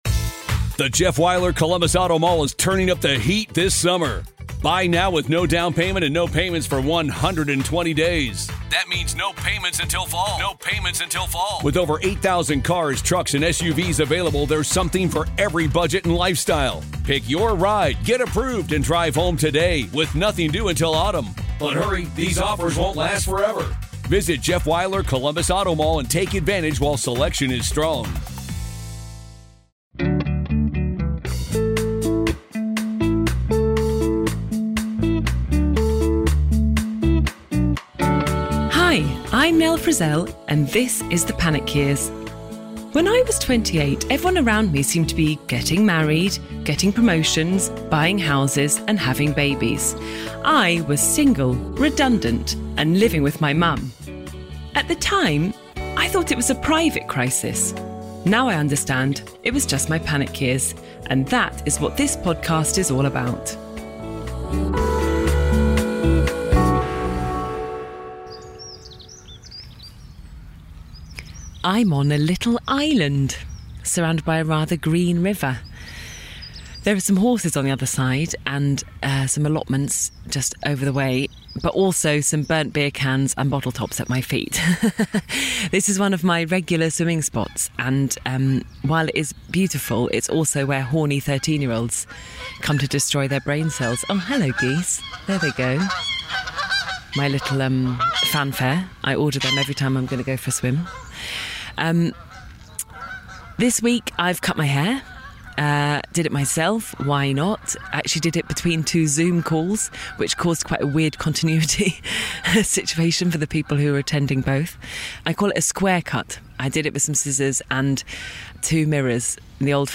This week I spoke to the the comedian, actor, writer and all round beautiful man, Rob Delaney.